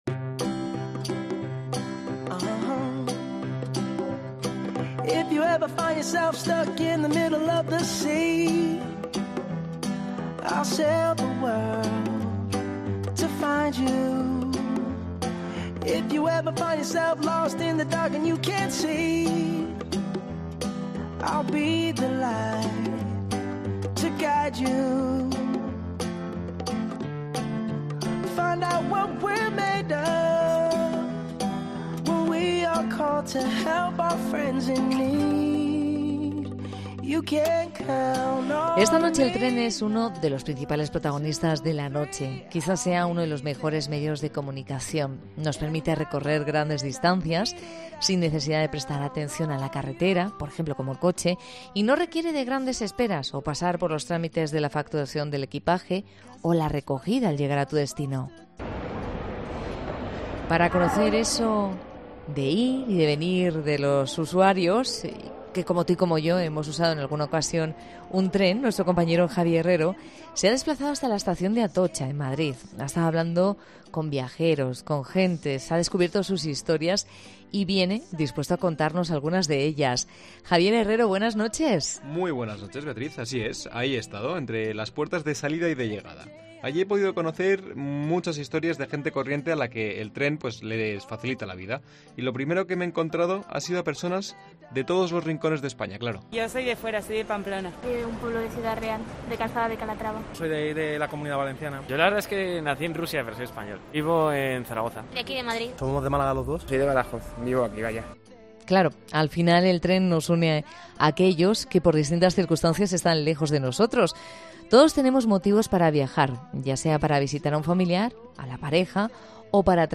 Te lo contaremos y escucharemos a los protagonistas.